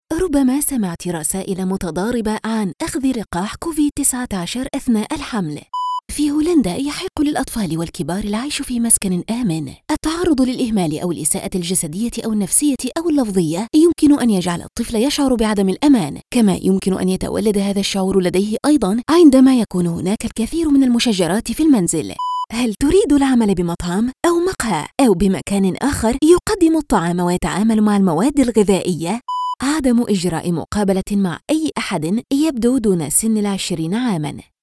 Female
Explainer Videos
All our voice actors have professional broadcast quality recording studios.
1102Arabic_Explainer_DemoReel.mp3